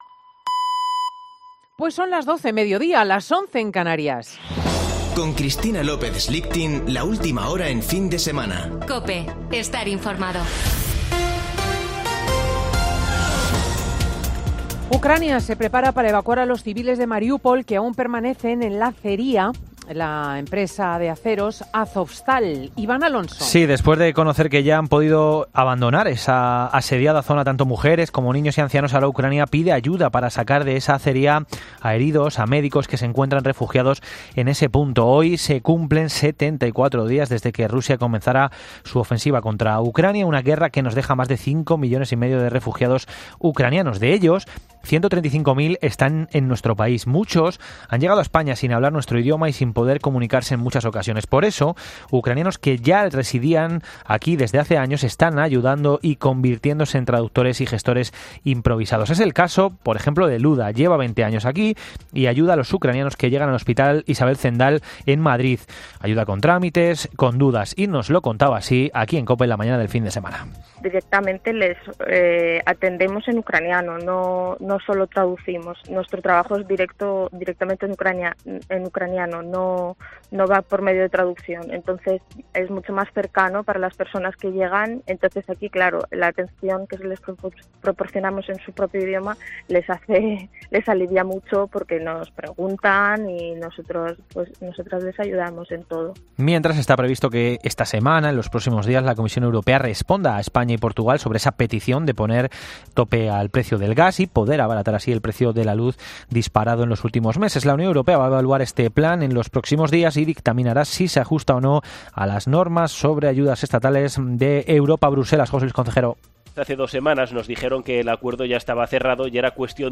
Boletín de noticias de COPE del 8 de mayo de 2022 a las 12.00 horas